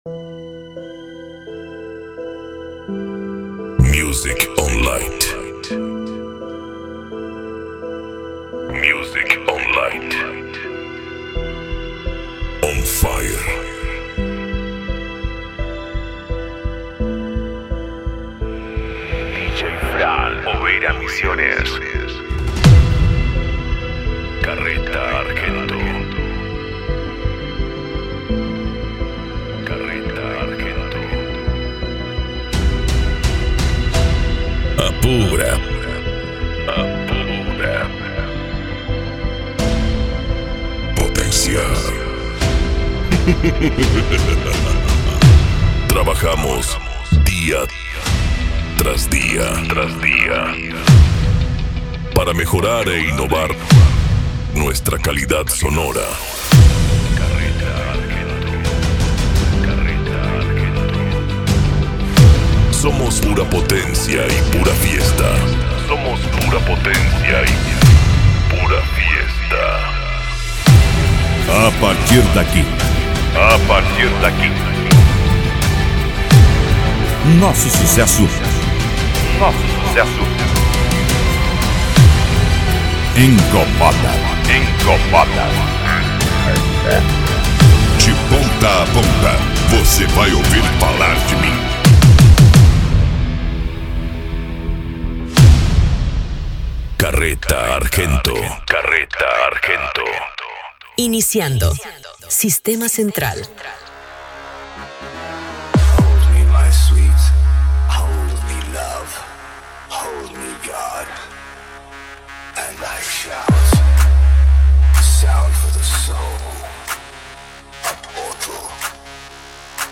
Bass
Euro Dance
Pagode
Remix